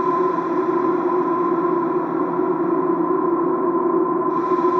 SS_CreepVoxLoopB-04.wav